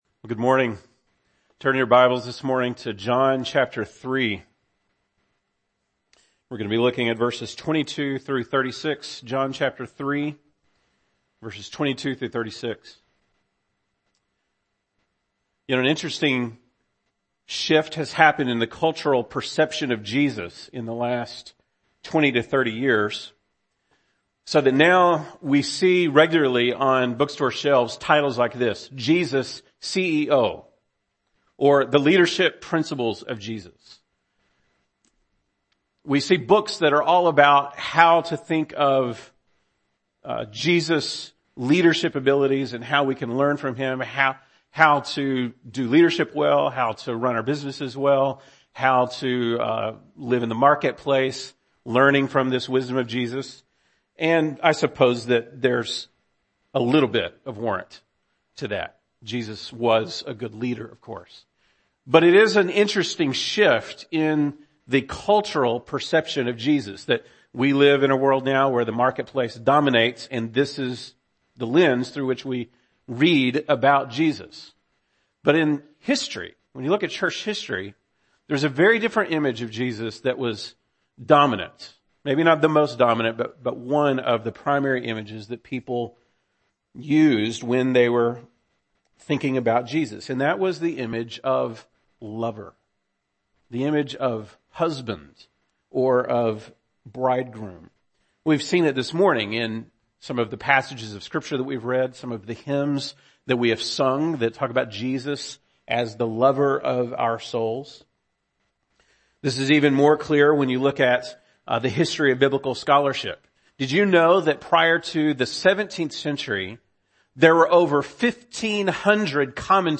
February 19, 2017 (Sunday Morning)